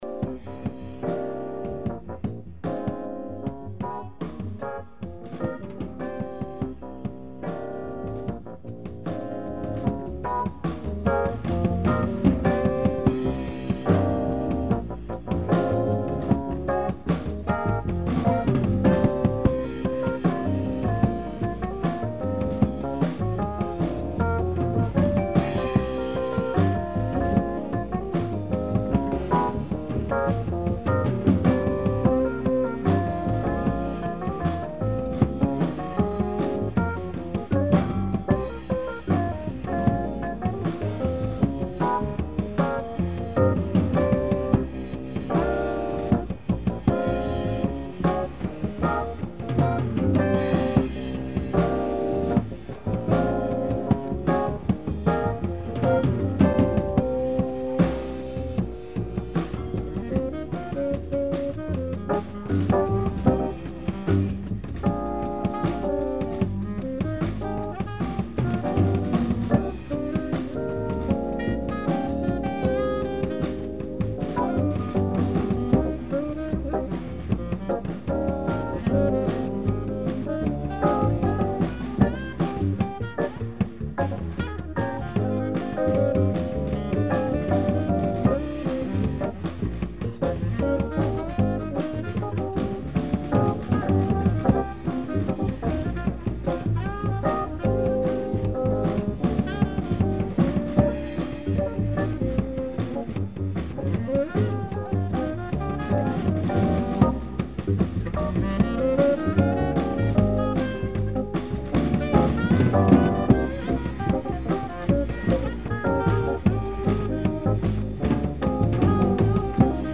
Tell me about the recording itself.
drums Holiday Inn